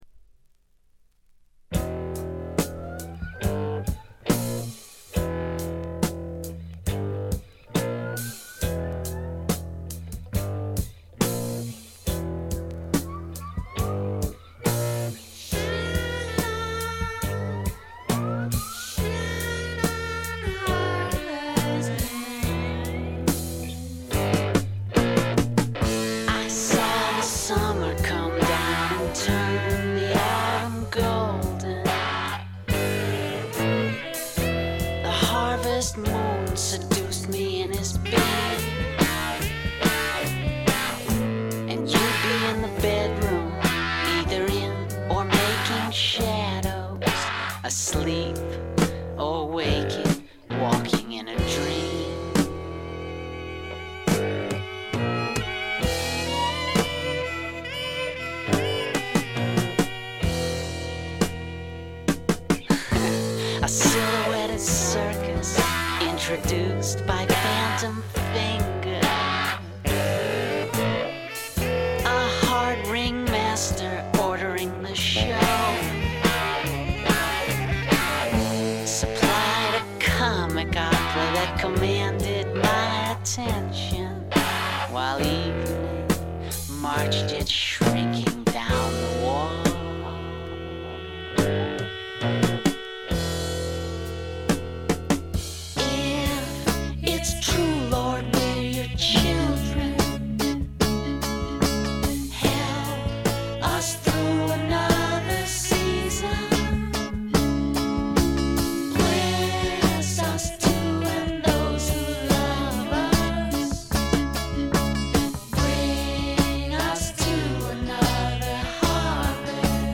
A4とB3でプツ音。
試聴曲は現品からの取り込み音源です。